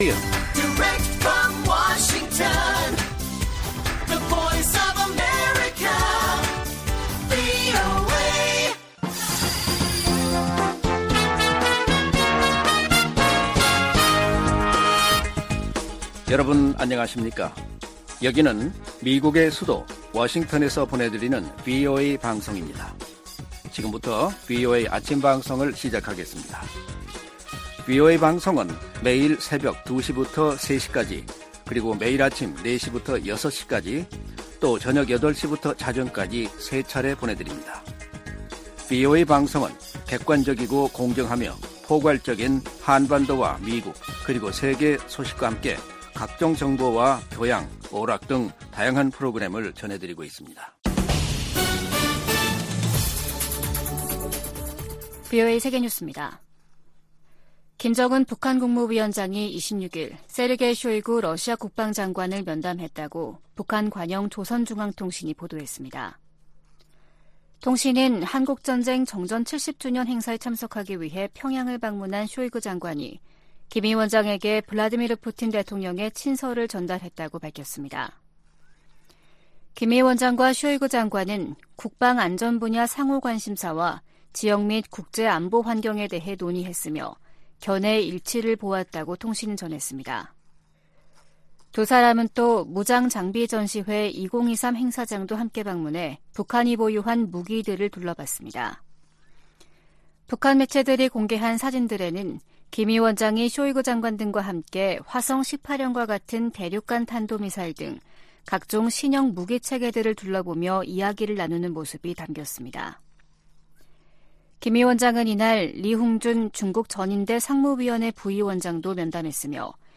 세계 뉴스와 함께 미국의 모든 것을 소개하는 '생방송 여기는 워싱턴입니다', 2023년 7월 28일 아침 방송입니다. '지구촌 오늘'에서는 우크라이나군이 남동부 전선을 중심으로 새로운 공세를 시작했다고 보도된 소식 전해드리고, '아메리카 나우'에서는 기준금리 0.25%P 인상 이야기 살펴보겠습니다.